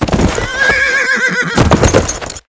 horses
rear1.ogg